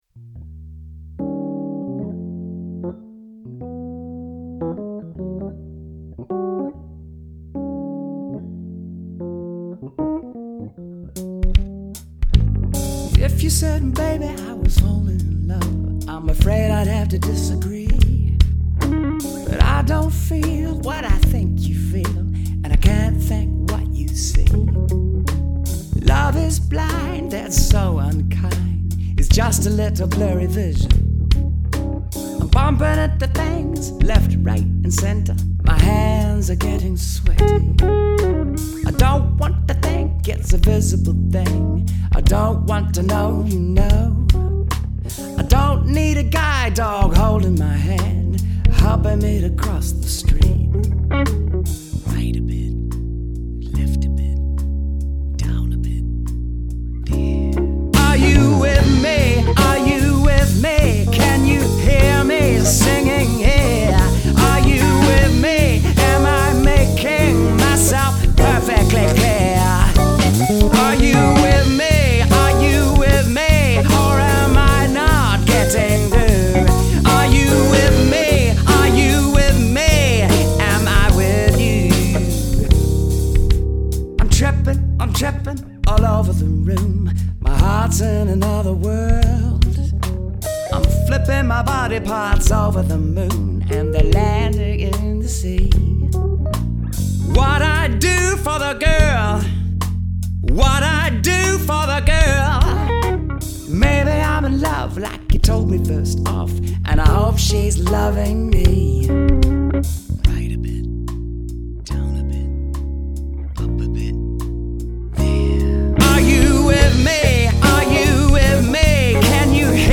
medium male vox, keys, bass, drums, guitar